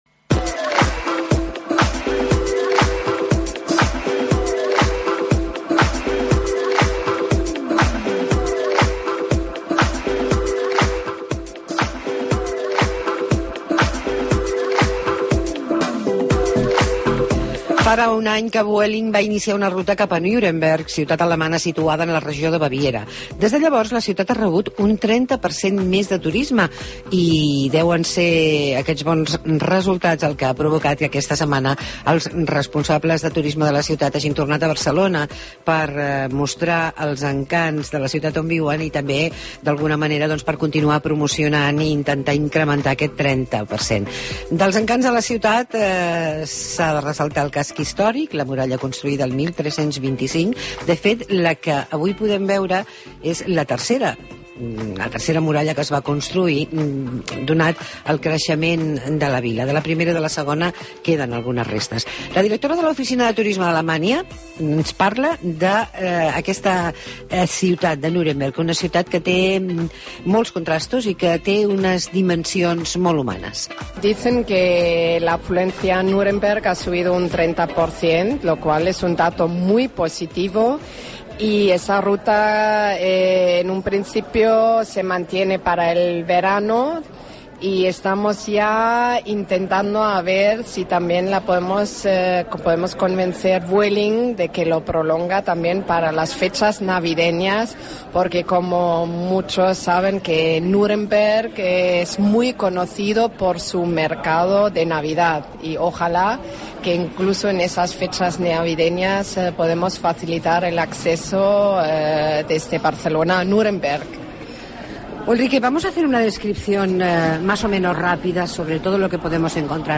Reportatge sobre Nuremberg i Ratisbona